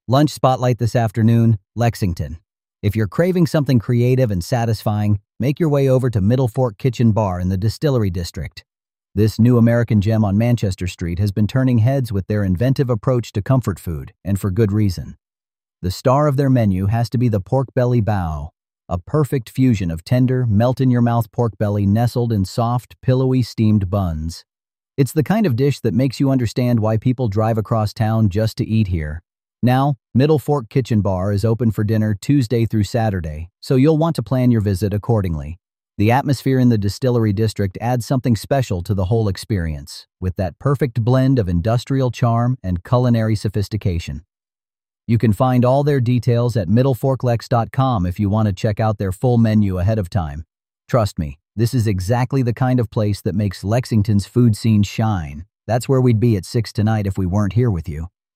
This transcript is from a recent on-air segment.
Voice synthesis via ElevenLabs; script via Claude.